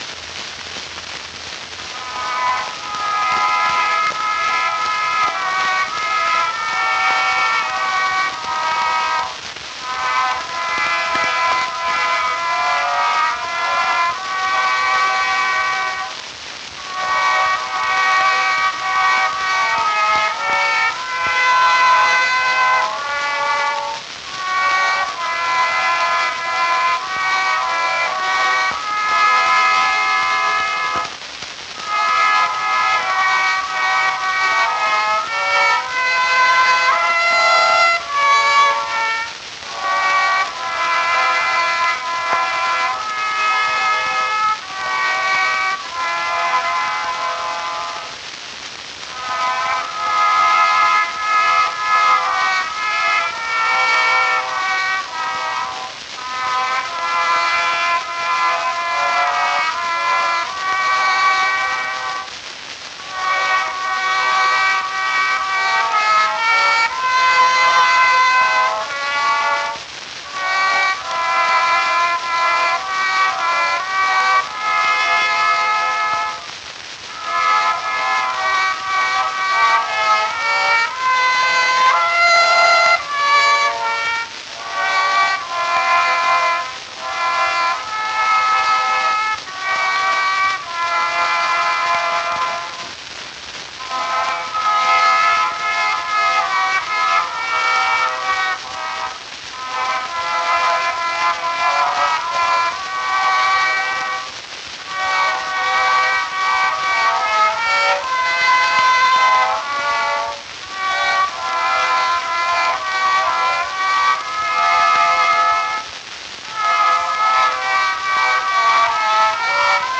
mit Klavierbegleitung: In einem kühlen Grunde
Edison Gold Moulded Record, German Series 12798, 1902.
Leider rauscht die Aufnahme ziemlich stark.